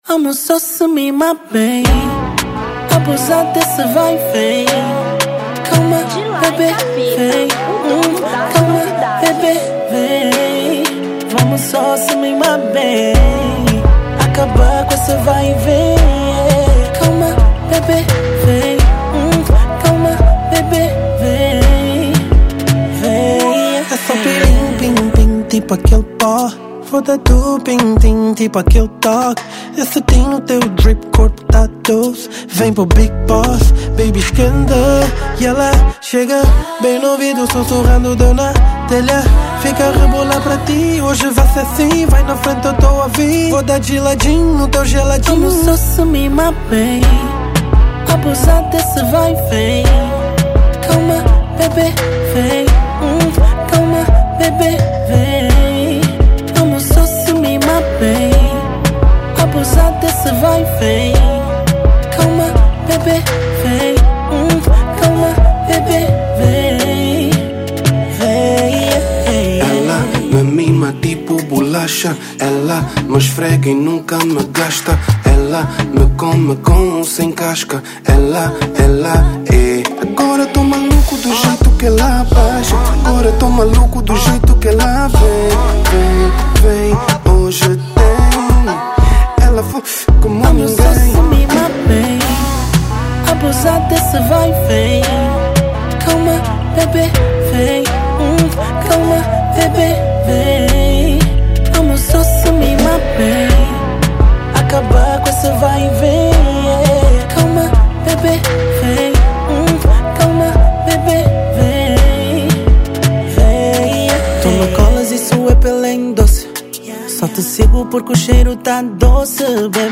Zouk 2025